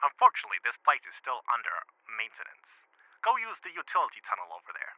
Voice Lines
Script Dialogue